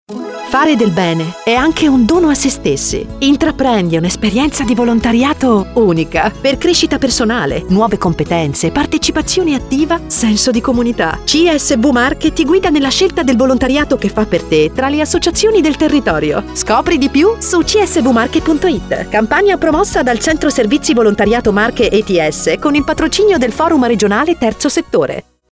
spot-radio_CSV-NOV-22.mp3